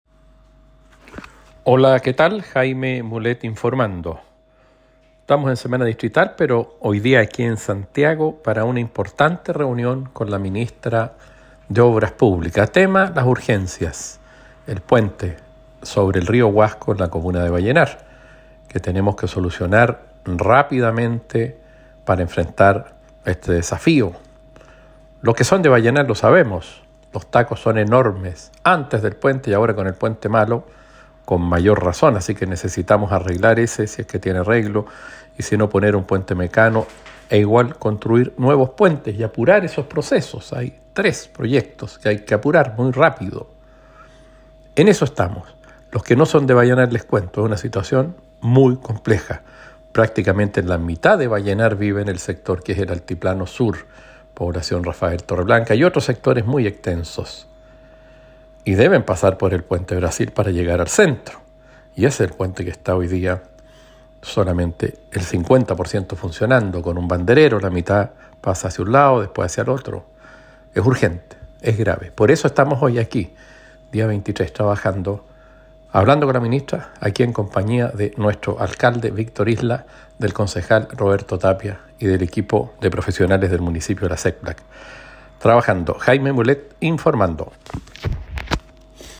Audio del Diputado Jaime Mulet Informando